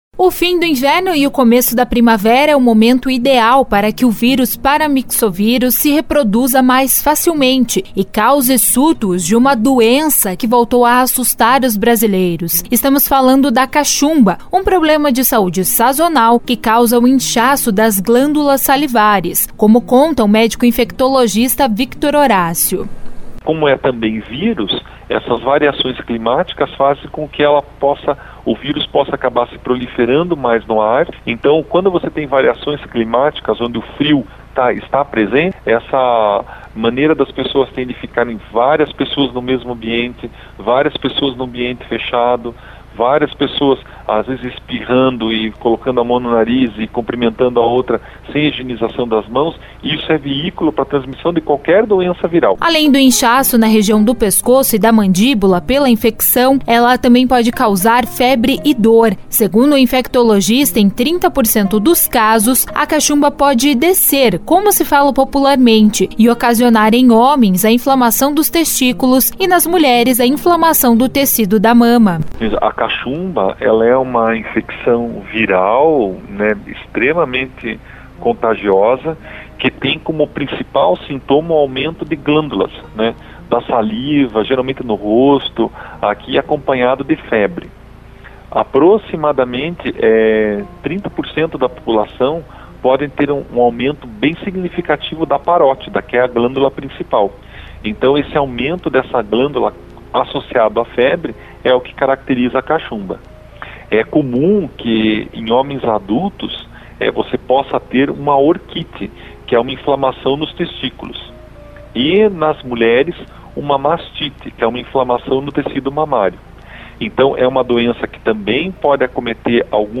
Reportagem 04- Caxumba